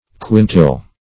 Search Result for " quintile" : The Collaborative International Dictionary of English v.0.48: Quintile \Quin"tile\, n. [F. quintil aspect, fr. L. quintus the fifth.]